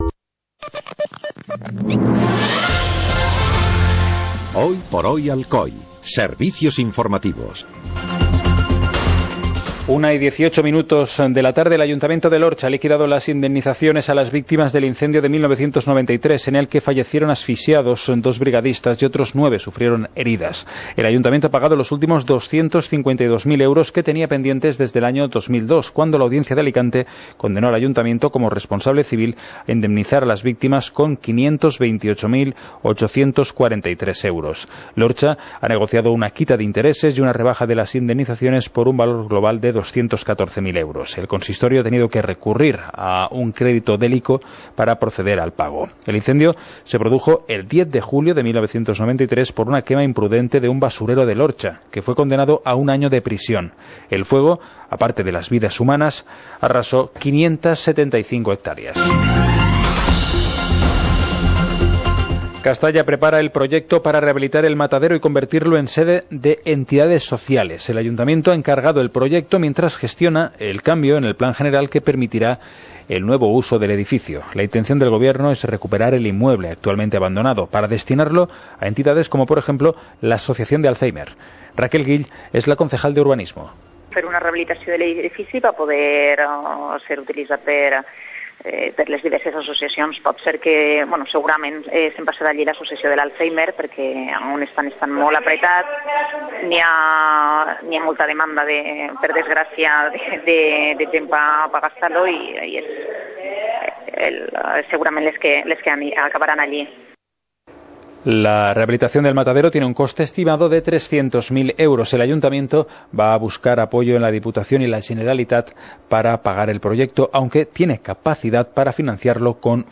Informativo comarcal - viernes, 15 de julio de 2016